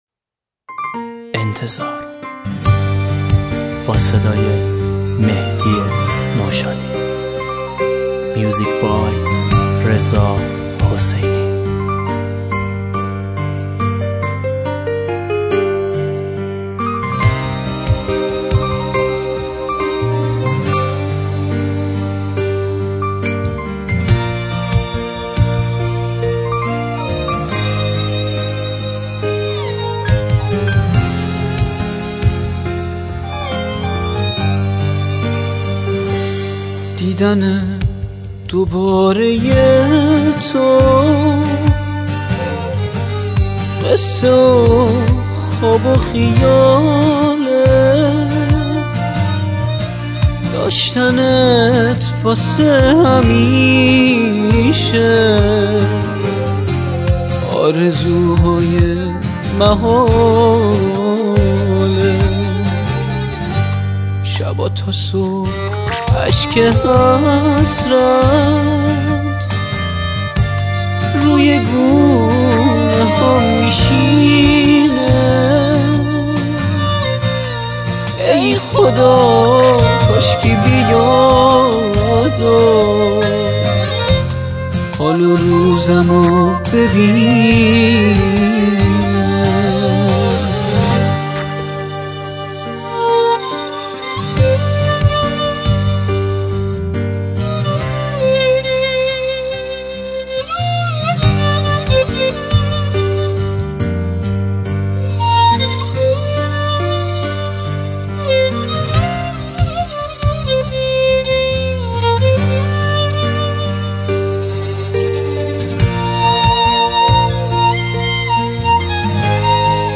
ویولن